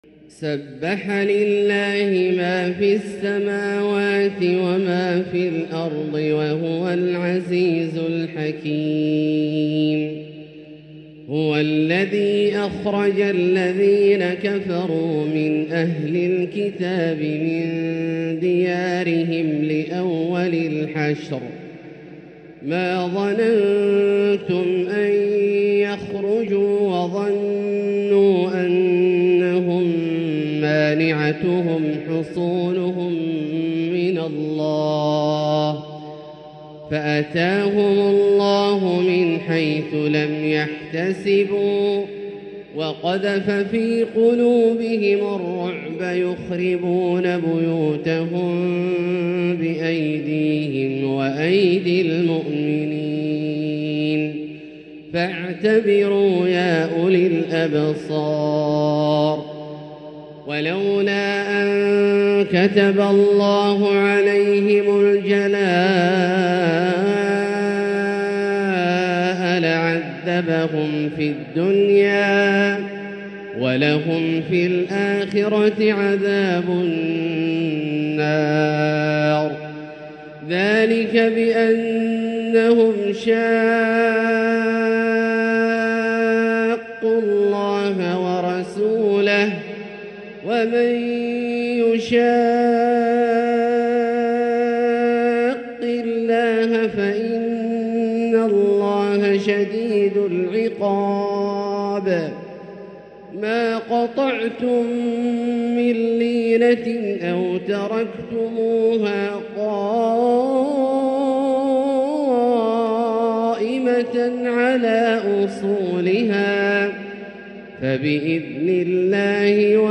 تلاوة إبداعية لـ سورة الحشر كاملة للشيخ د. عبدالله الجهني من المسجد الحرام | Surat Al-Hashr > تصوير مرئي للسور الكاملة من المسجد الحرام 🕋 > المزيد - تلاوات عبدالله الجهني